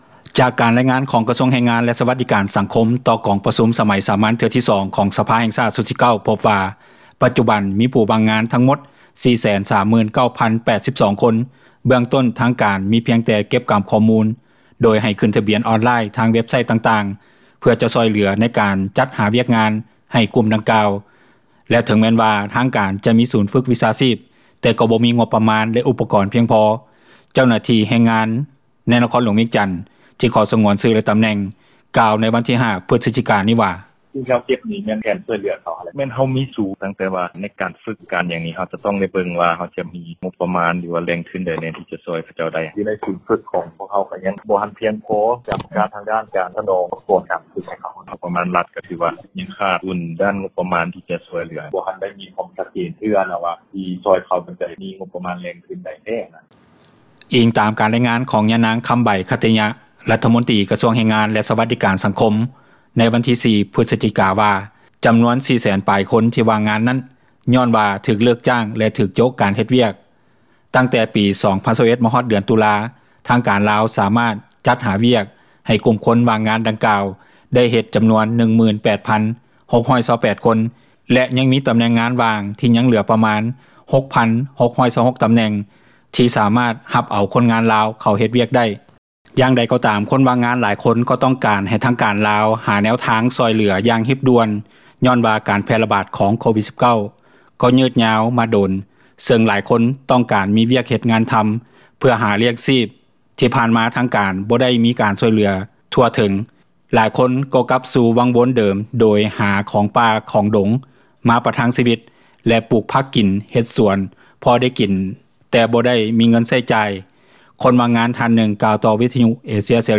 ຄົນຫວ່າງງານທ່ານນຶ່ງກ່າວຕໍ່ວິທຍຸເອເຊັຽເສຣີໃນມື້ດຽວກັນວ່າ: